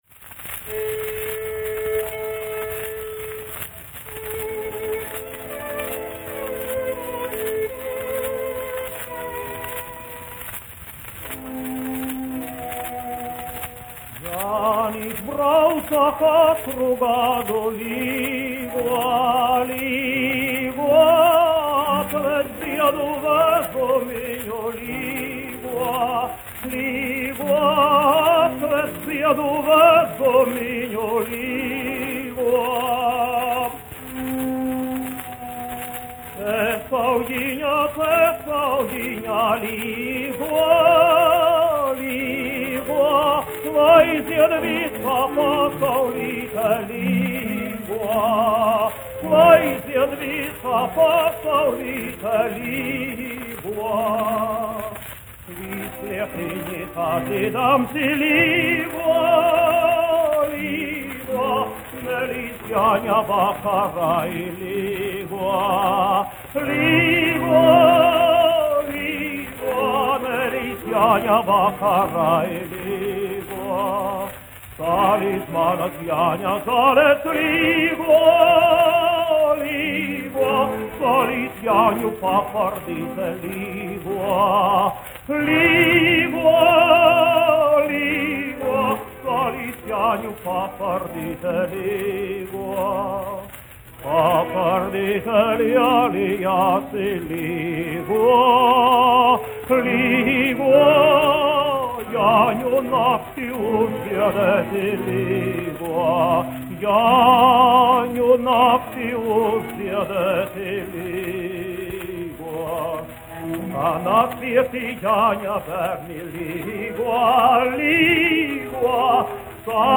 Lūcija Garūta, 1902-1977, aranžētājs
1 skpl. : analogs, 78 apgr/min, mono ; 25 cm
Latviešu tautasdziesmas
Dziesmas (augsta balss) ar instrumentālu ansambli
Skaņuplate